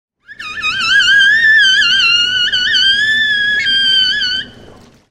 Звуки касаток
Звуки пения косатки